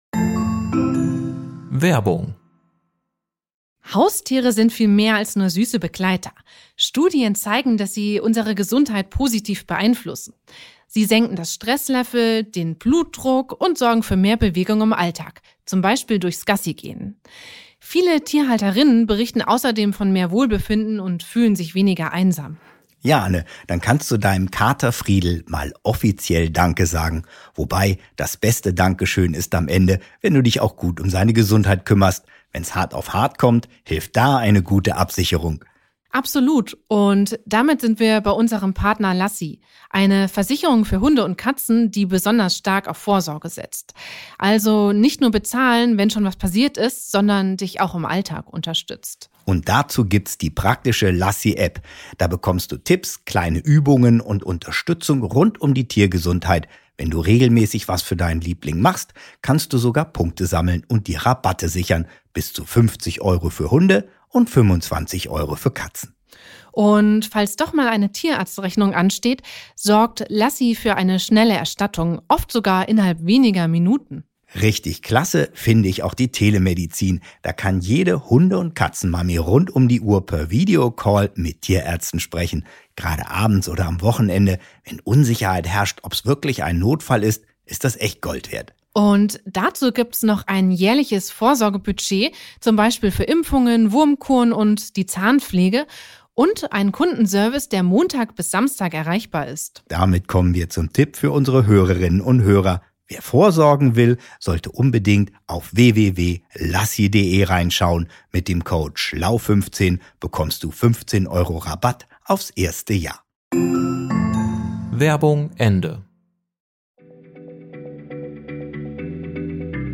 Im ersten Teil unserer dreiteiligen Miniserie über „Außerirdische“ erklärt sie, mit welchen Techniken sich lebendige Organismen in fernsten Galaxien aufspüren lassen. Das Gespräch